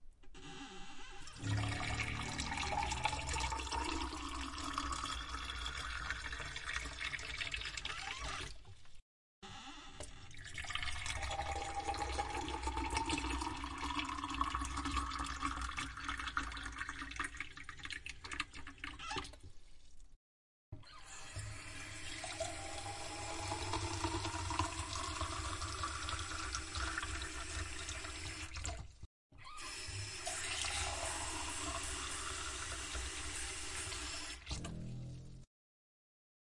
家庭 " 厨房水龙头运行速度时快时慢，灌满了水杯
描述：水龙头开得快，装满一杯，放下，水龙头开得慢一点，装满另一杯，倒掉，再装，放下，关上水龙头，倒掉杯子
标签： 厨房 排空 关闭 填充 玻璃 速度快 跑动 转身慢 水龙头
声道立体声